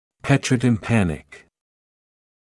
[ˌpetrəutɪm’pænɪk][ˌпэтроутим’пэник]каменисто-барабанный
petrotympanic.mp3